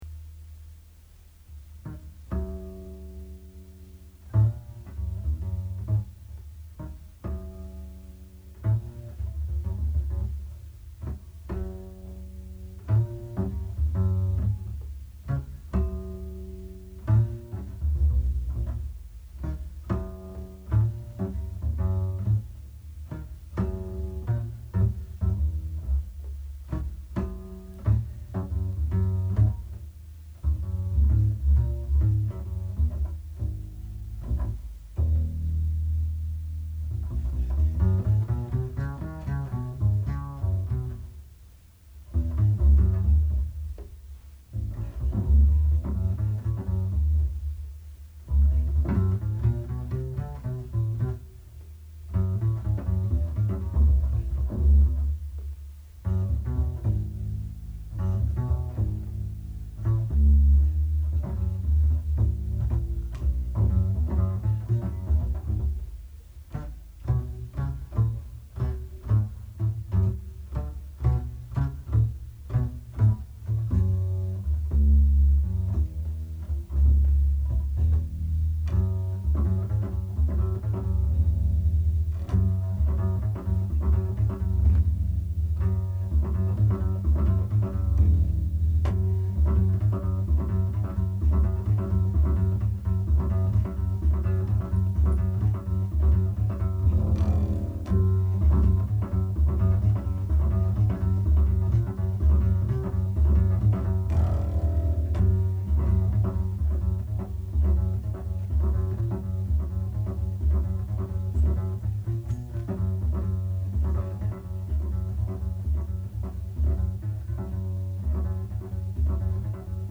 آلبوم جاز